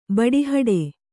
♪ baḍihaḍe